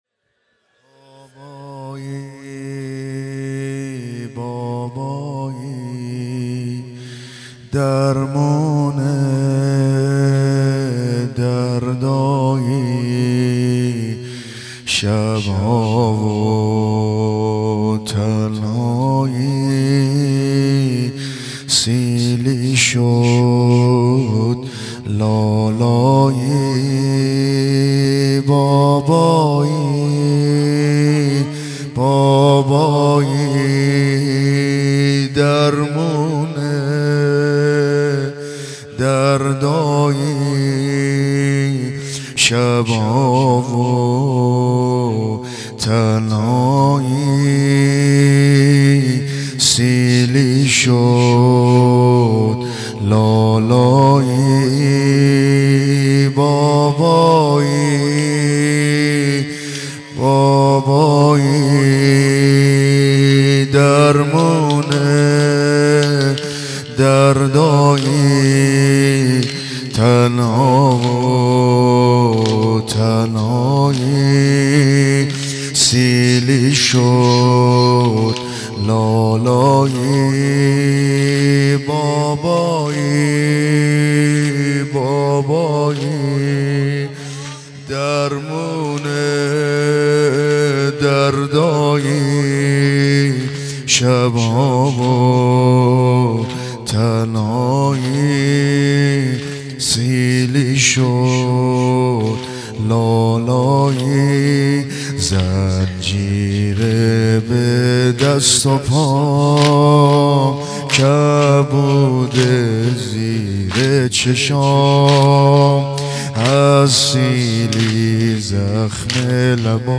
عقیق: مراسم عزاداری دهه دوم صفر در هیئت طفلان مسلم مرکز آموزش قرآن و معارف اسلامی برگزار شد.
نوحه